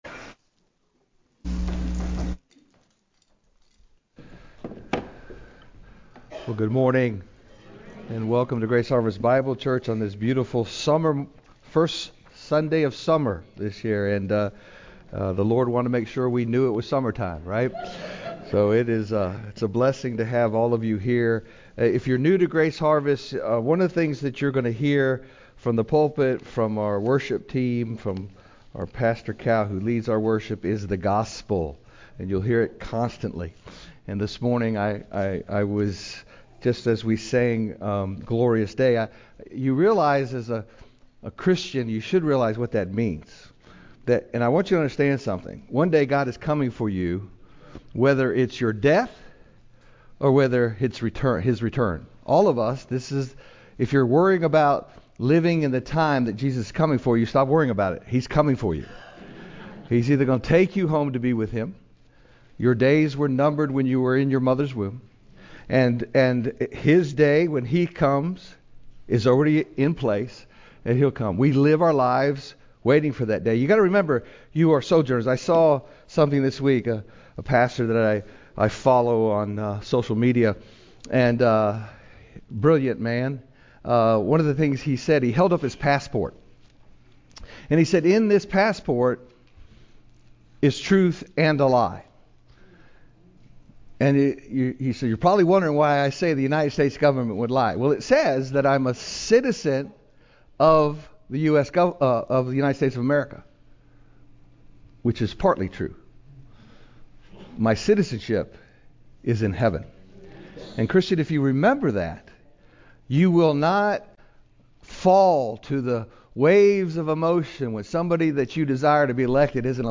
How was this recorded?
GHBC-Service-Laws-Relationship-to-Sin-Romans-77-13-CD.mp3